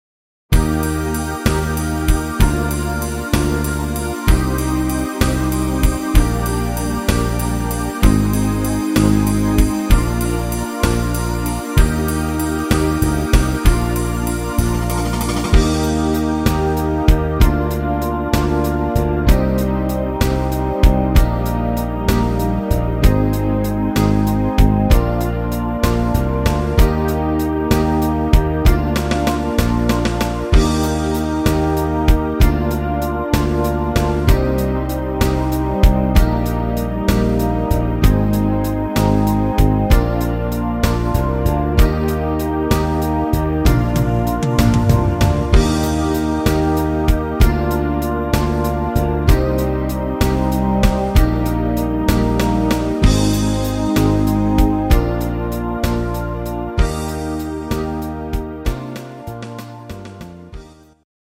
Instrumental Saxophon